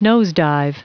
Prononciation du mot nosedive en anglais (fichier audio)
Prononciation du mot : nosedive
nosedive.wav